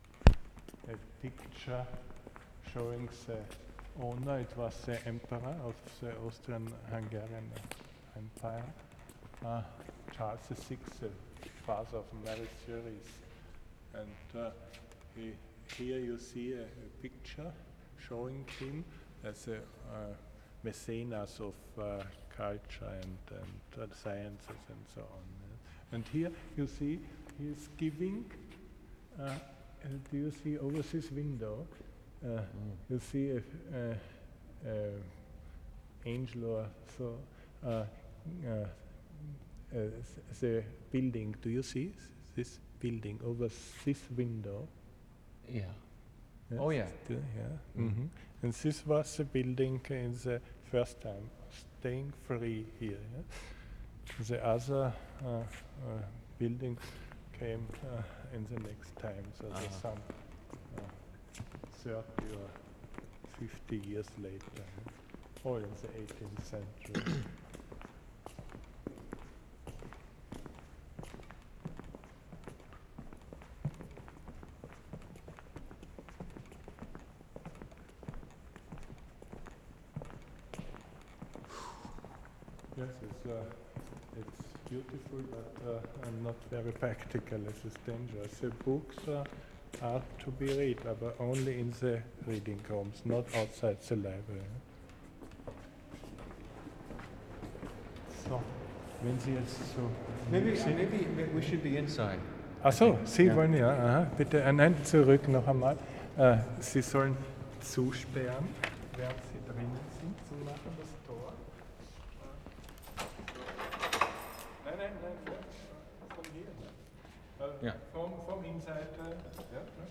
OLD READING ROOM, NATIONAL LIBRARY, begins with conversation with guide, then walking over squeaky floors to the reading room.